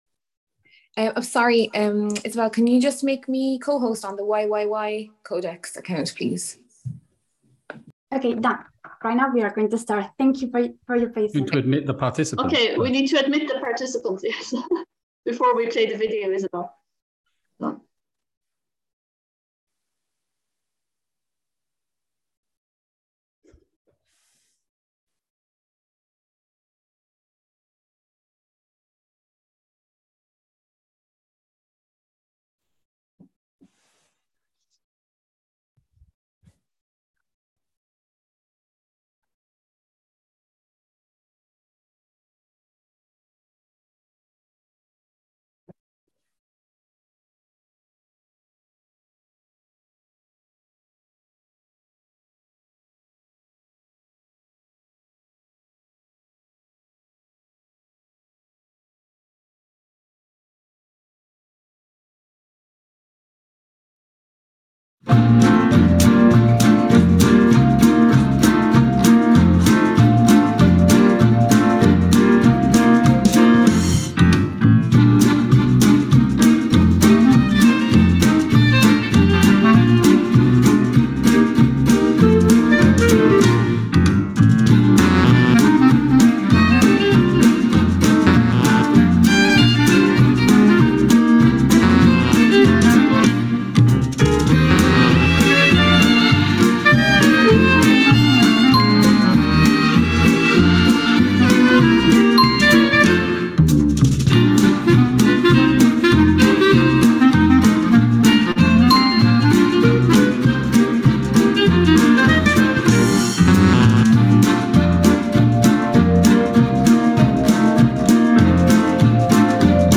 A live event held online for Codex Members and Observers on 29 July 2022 provided the first details of what the 45th Codex Alimentarius Commission will look like as a physical session scheduled for Rome beginning 21 November.